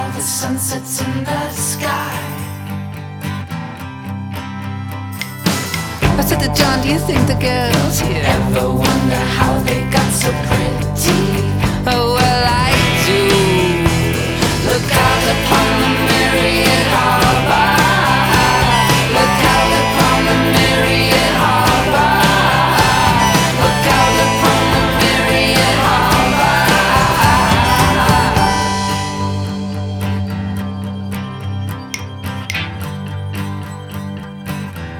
Alternative Rock
Indie Rock
Жанр: Рок / Альтернатива